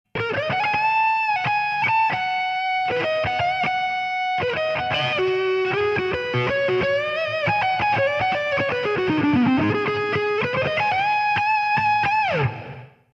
RAT distortion Valve Amp on clean Peavey Wolfgang special